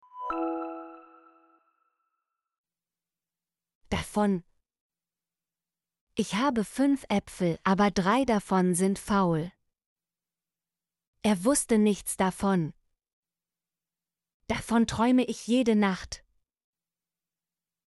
davon - Example Sentences & Pronunciation, German Frequency List